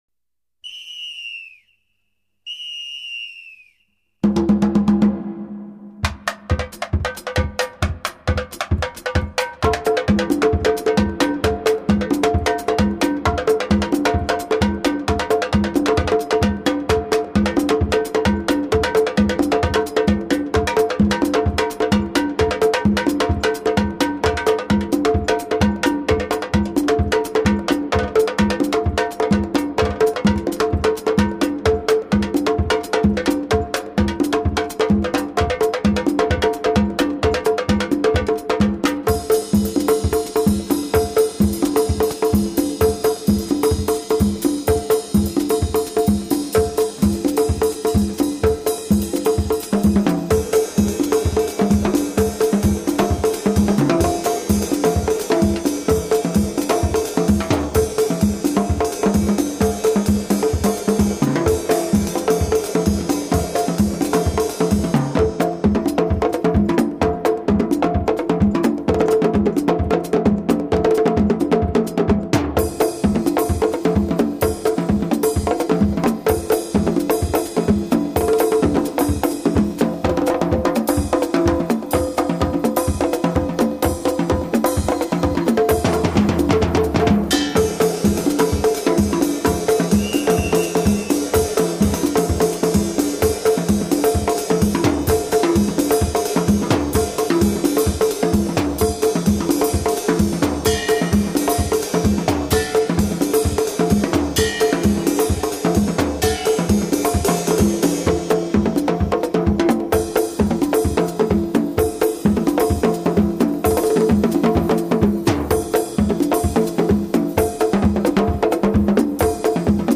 唱片品质： DDD 24Bit